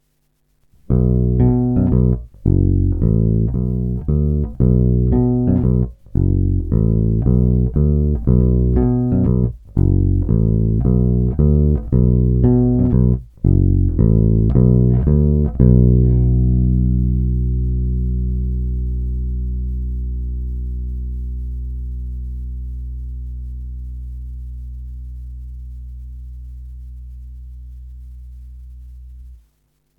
Tónová clona plně otevřená.
Hráno blízko krku
Hráno nad snímačem
Hráno mezi snímačem a kobylkou